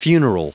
Prononciation du mot funeral en anglais (fichier audio)
Prononciation du mot : funeral